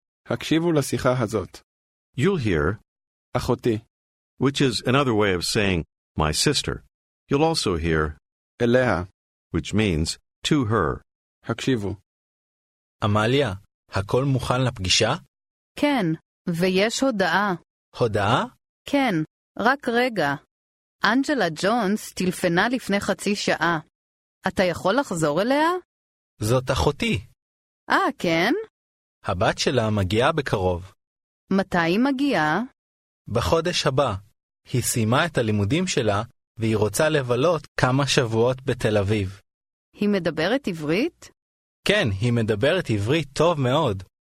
Аудио курс для самостоятельного изучения иврита.